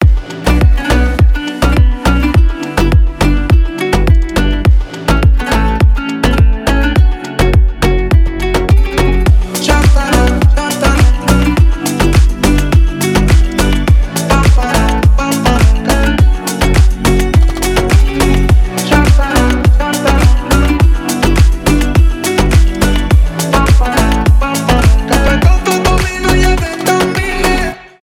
dance pop
танцевальные
восточные